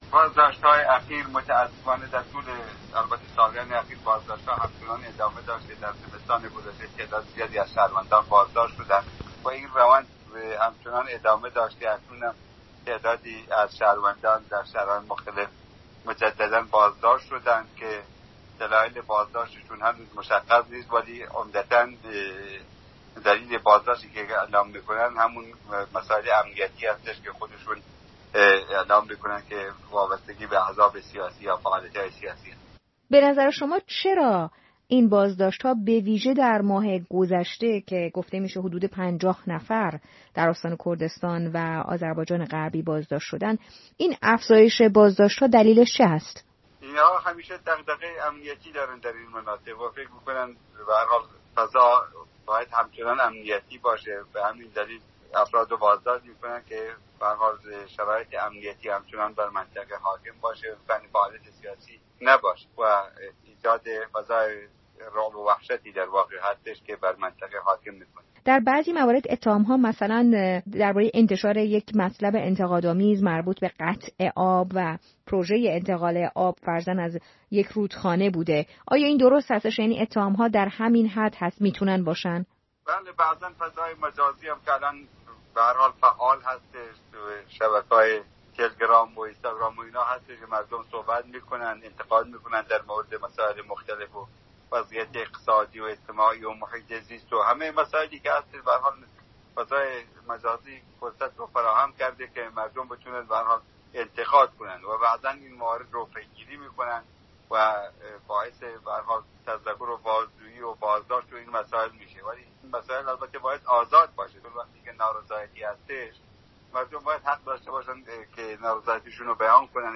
به گفته سازمان حقوق بشری هه‌نگاو، در ماه گذشته میلادی دهها تن از شهروندان کرد ایران بازداشت شده اند. زمستان گذشته هم در موردی مشابه، دهها تن از شهروندان کرد ایران دستکم در ۱۹ شهر کشور بازداشت شدند. گفت‌وگوی